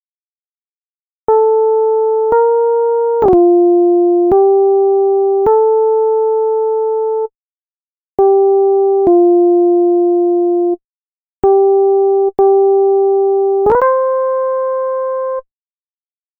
Key written in: C Major
Each recording below is single part only.